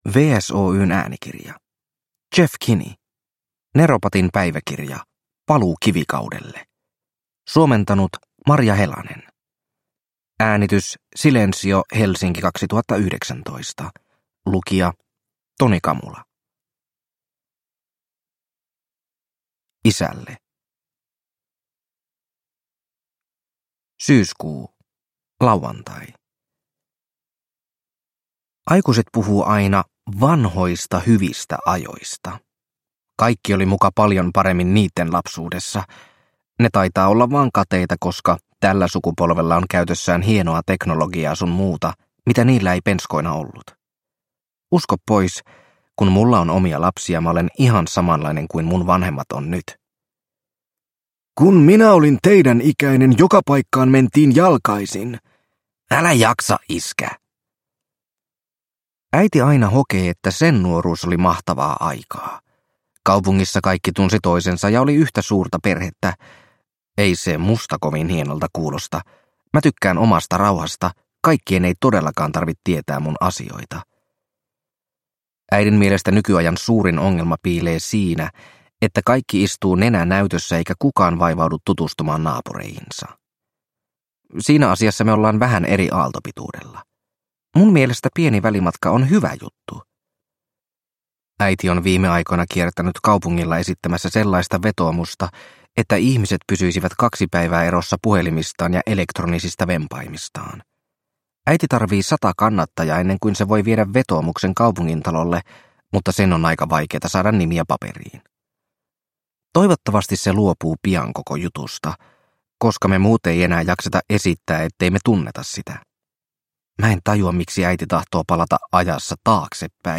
Neropatin päiväkirja: Paluu kivikaudelle – Ljudbok – Laddas ner